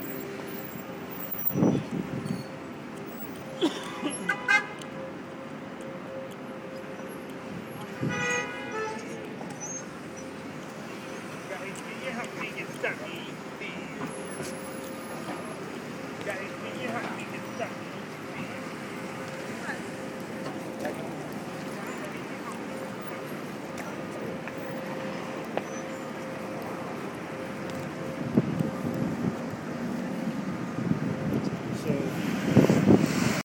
Field Recording